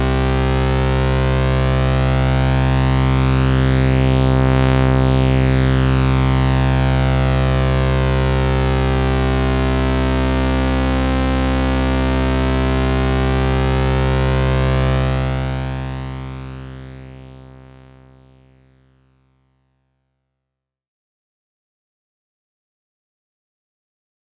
Here you can see that I have used Logic’s ES2 synth and have patched a simple sine wave based LFO to the master pan control using the mod matrix.
The ES2 auto panning in action.